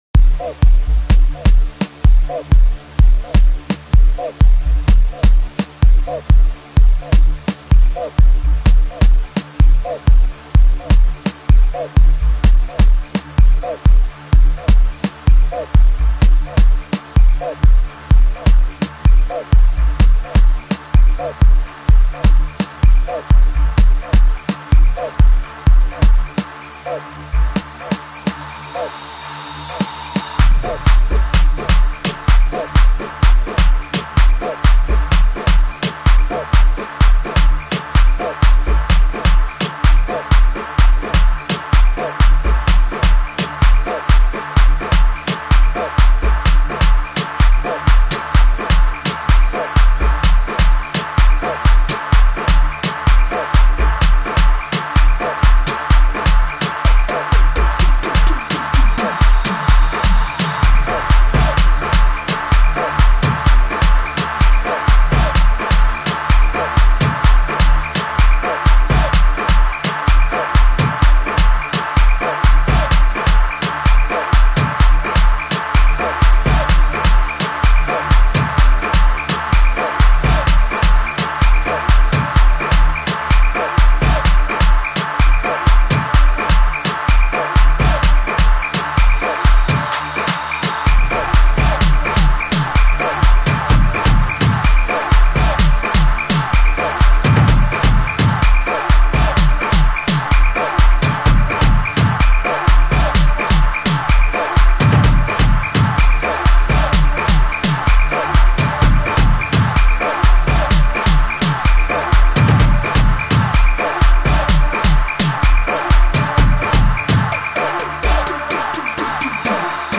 06:37 Genre : Gqom Size